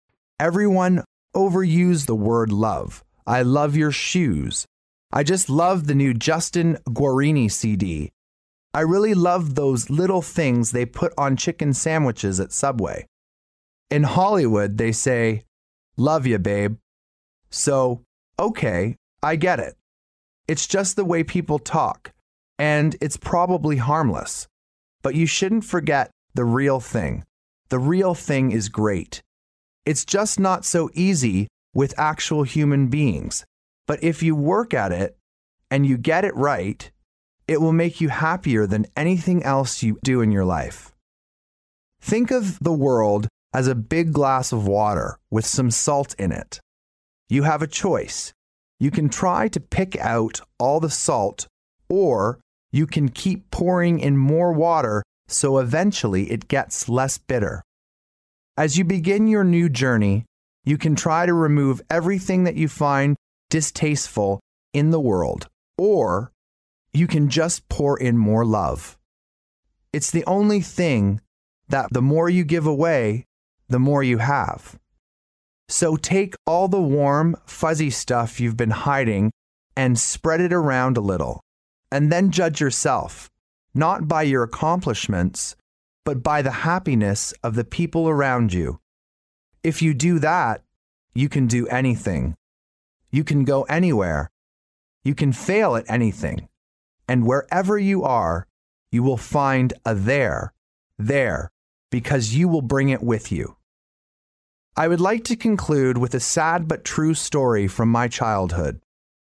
名校励志英语演讲 94:如何实现你的梦想 听力文件下载—在线英语听力室